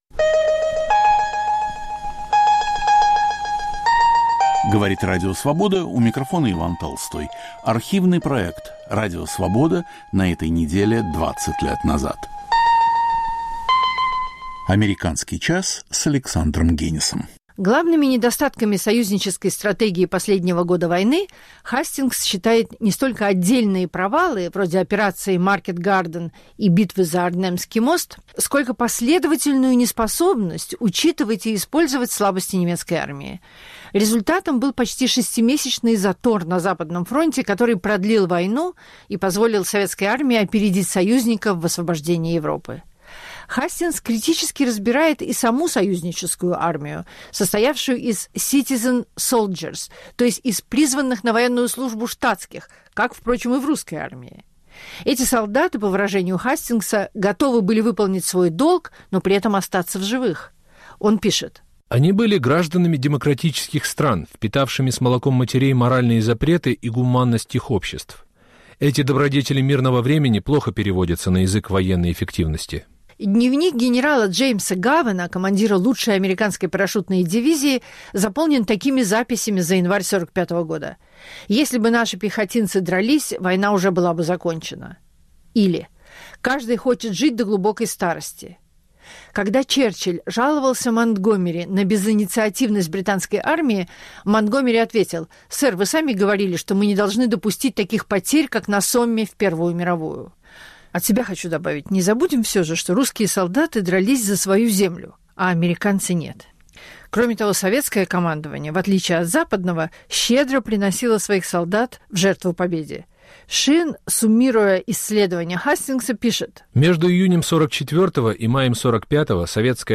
Автор и ведущий Александр Генис.